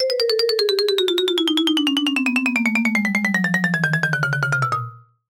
comedy_marimba_descend_004